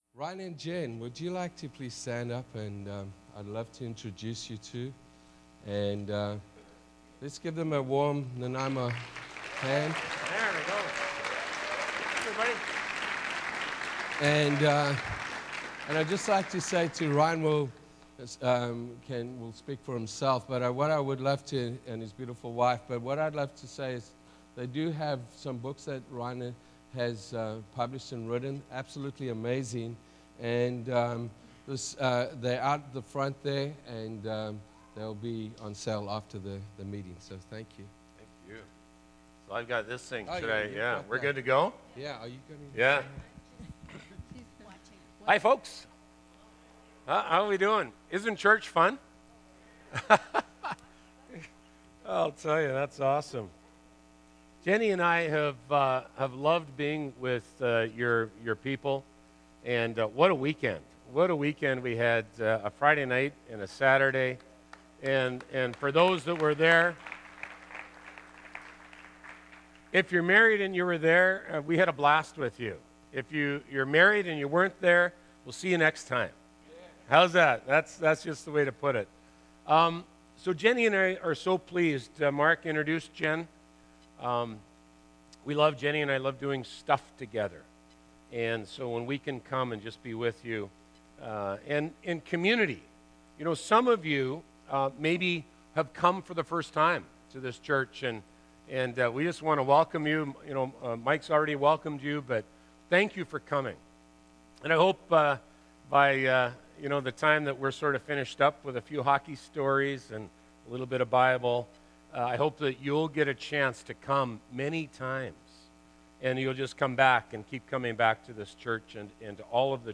A NHL Players Journey - Sermons - Oceanside Church -